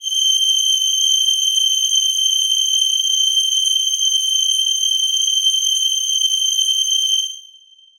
Choir Piano
G7.wav